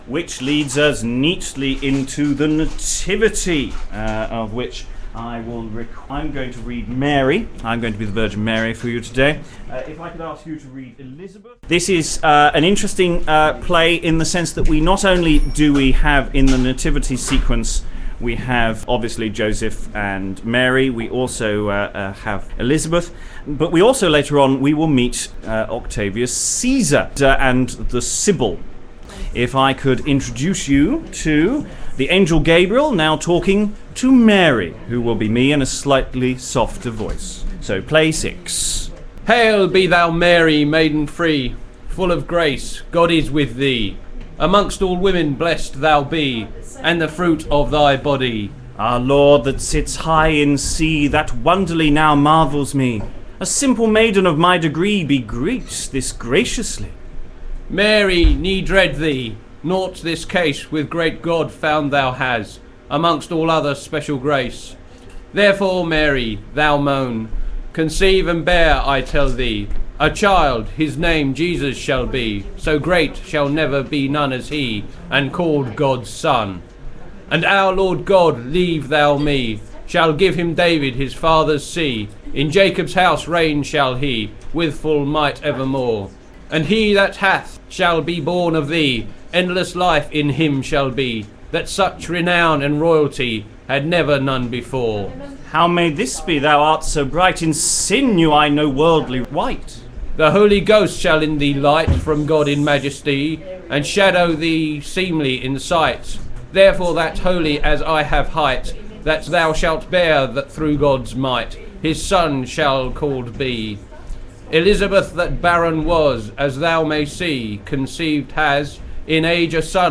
Exploring the Chester Mystery Plays is a series of live streamed events where the Chester plays are taken apart with readers and commentary. Rough round the edges, edited versions of these events are now being posted online. This post covers the start of play 6, The Nativity, where Octavius Caesar consults the Sybil about the coming of Jesus.